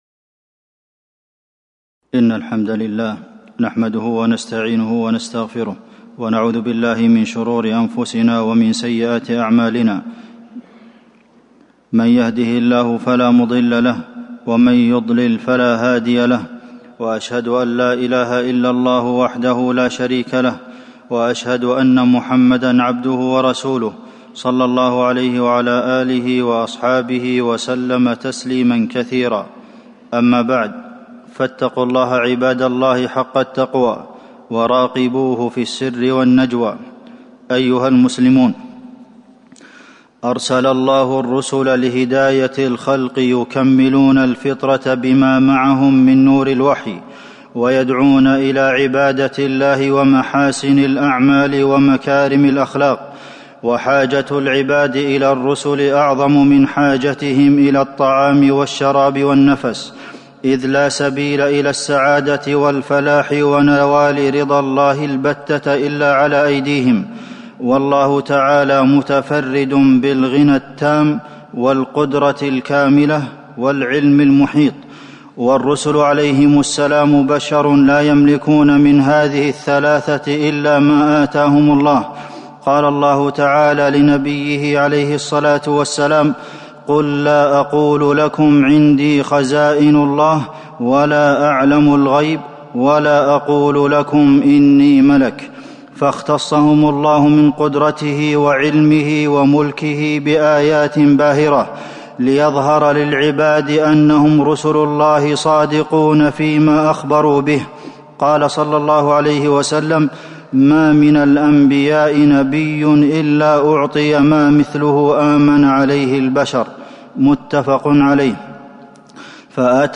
تاريخ النشر ٢١ ربيع الثاني ١٤٤٣ هـ المكان: المسجد النبوي الشيخ: فضيلة الشيخ د. عبدالمحسن بن محمد القاسم فضيلة الشيخ د. عبدالمحسن بن محمد القاسم دلائل النبوة